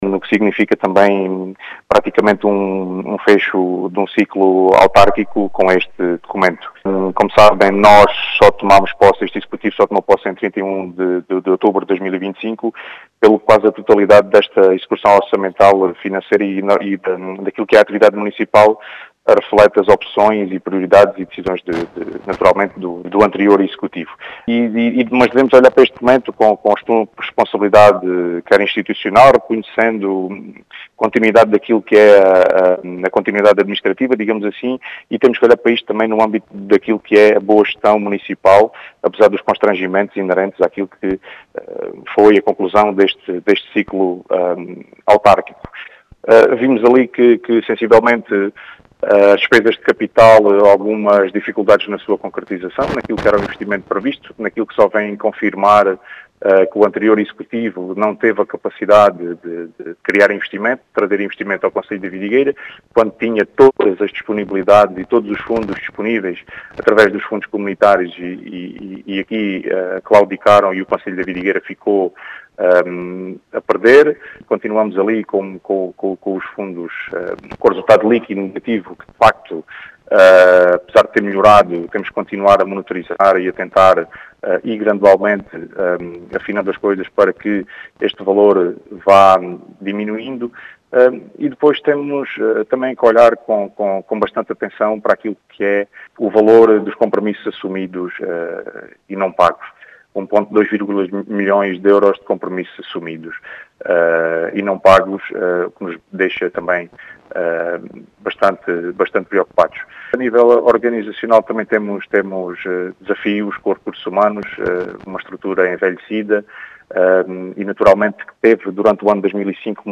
As explicações foram deixadas em entrevista à Rádio Vidigueira, pelo presidente da Câmara de Vidigueira, Ricardo Bonito, que fala “no fim de um ciclo autárquico”, apontando que o anterior executivo “não teve a capacidade de criar investimento” no concelho.
Ricardo-Bonito-2.mp3